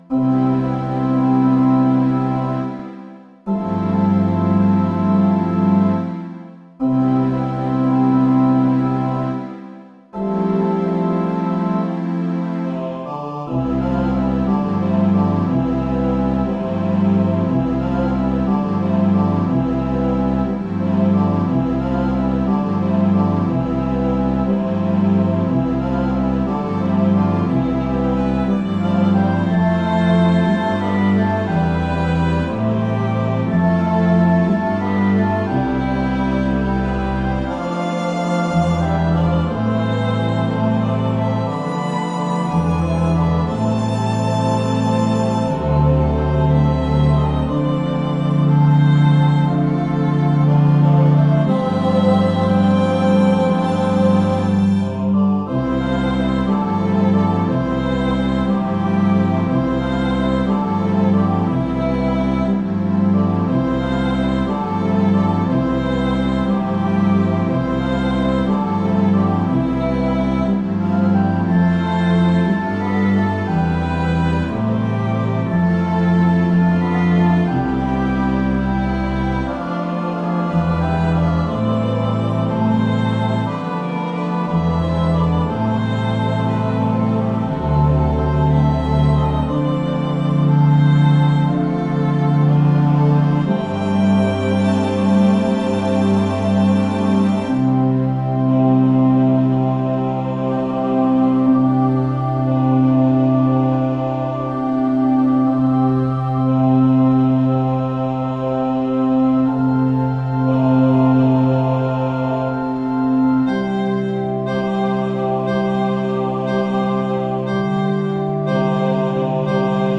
Composer’s original recording:
Arrangement for choir, organ and violin (as shown above):